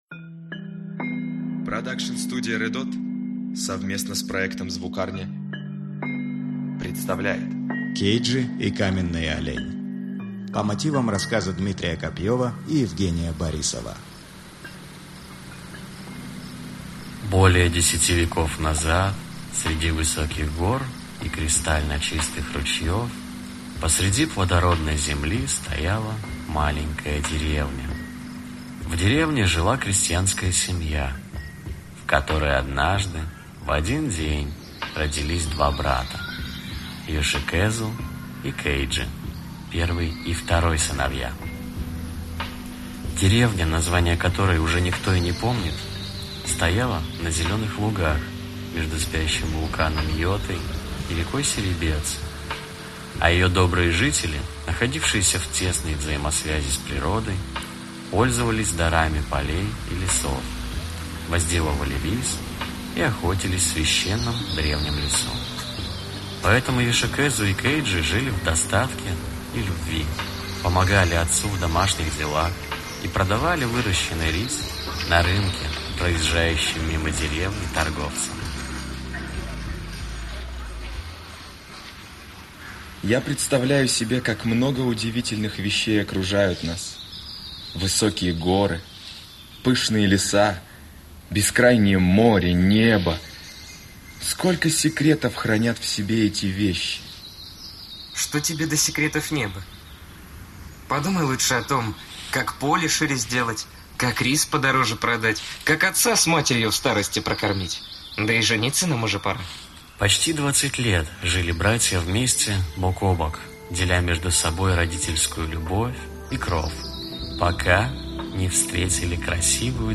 Аудиокнига Кейджи | Библиотека аудиокниг